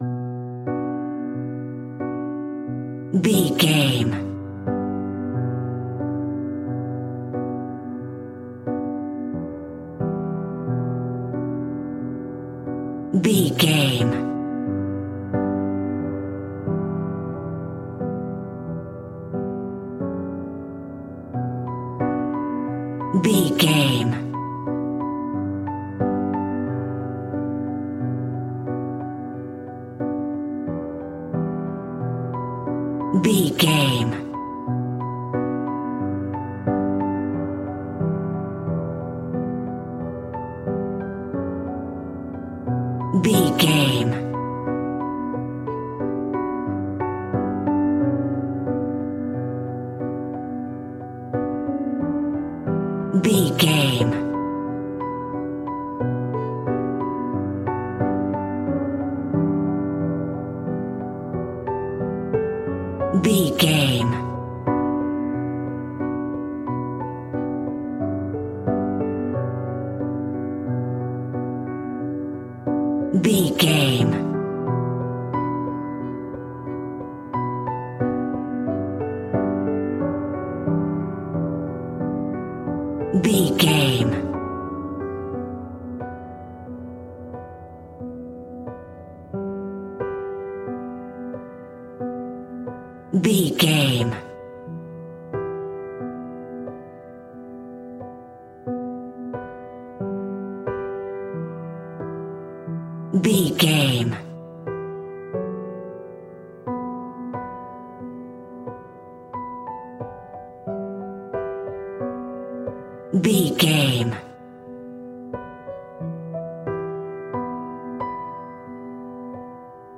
A bouncy and fun classical piano melody.
Regal and romantic, a classy piece of classical music.
Aeolian/Minor